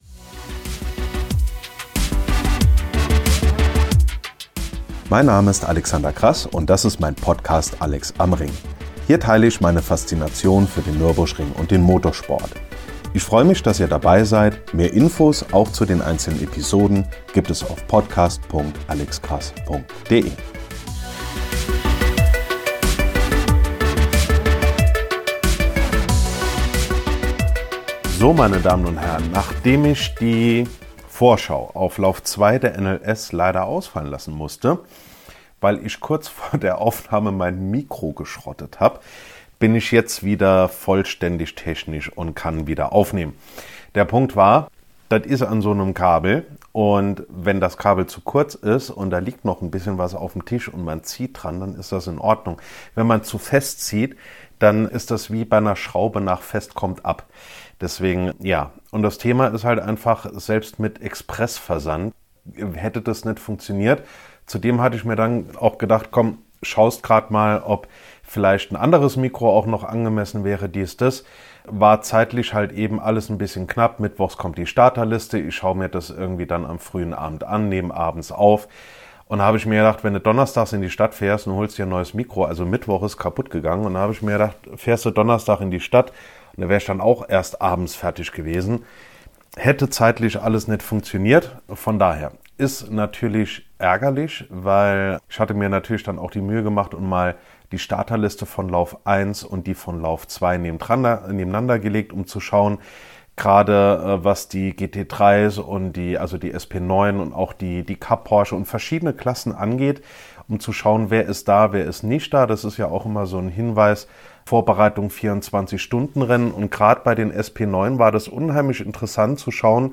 In diesen Wochen vor 100 Jahren begann die Geschichte des Nürburgrings - Grund genug für eine eigene Kolumne, von der ich in dieser Episode den ersten Teil vorlese.